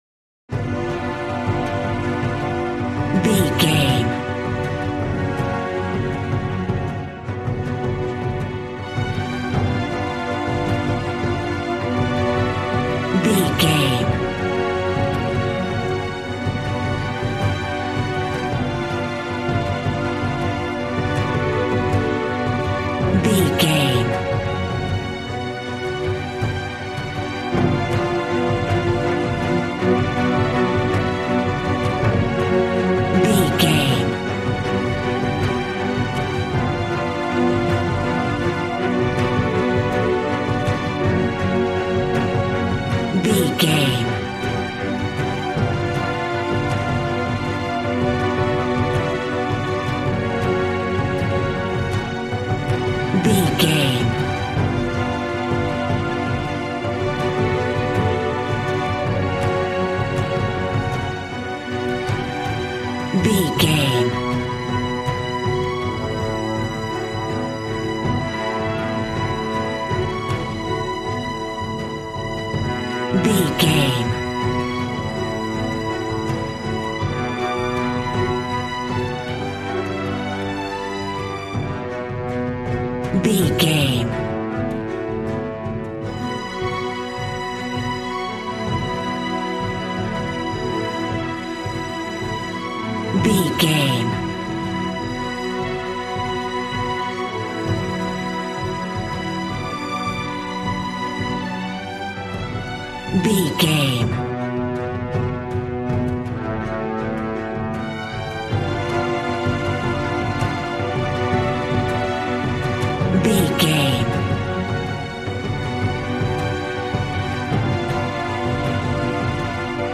A dark and scary piece of tense classical music.
Aeolian/Minor
suspense
piano
synthesiser